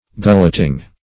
Search Result for " gulleting" : The Collaborative International Dictionary of English v.0.48: Gulleting \Gul"let*ing\, n. (Engin.) A system of excavating by means of gullets or channels.